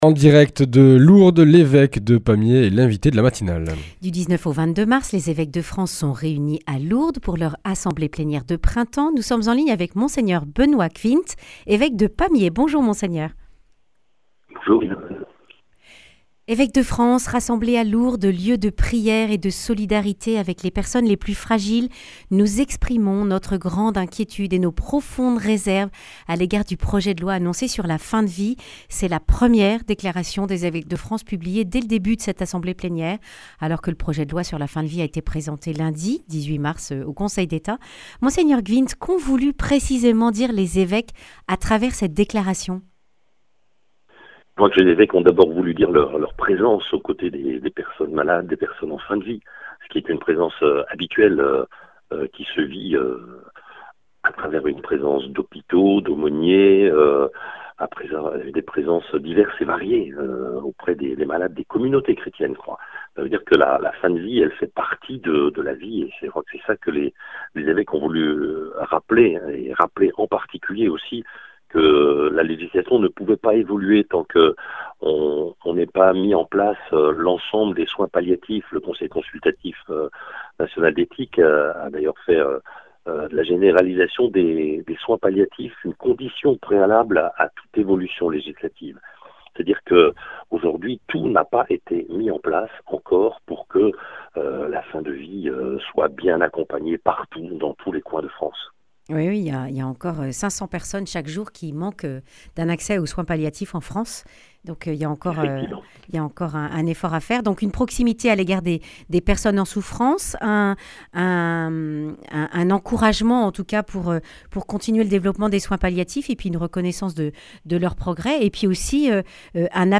Réunis à Lourdes pour leur assemblée de printemps, les évêques s’intéressent notamment au diaconat permanent. Défense de la vie, réorganisation de la CEF, baptêmes d’adultes à Pâques sont les autres sujets évoqués. Monseigneur Benoit Gshwind, évêque de Pamiers, dresse le bilan de cette assemblée.
Le grand entretien